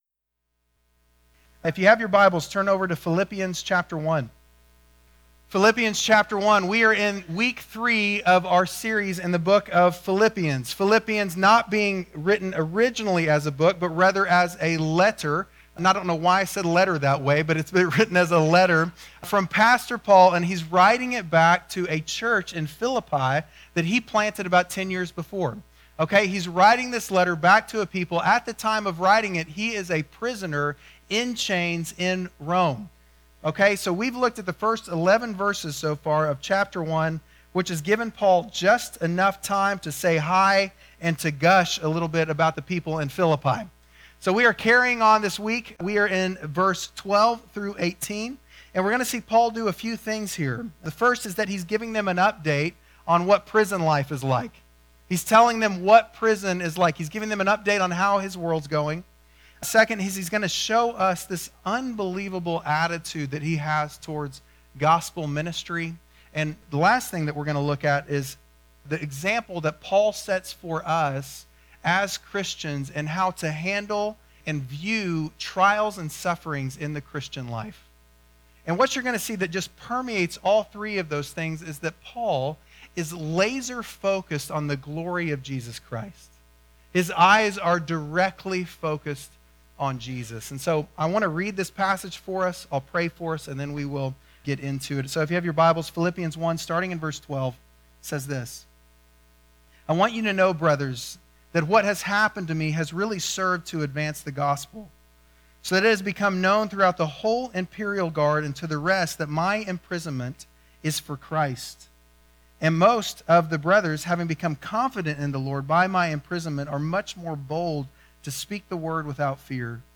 Bible Text: Philippians 1:12-18 | Preacher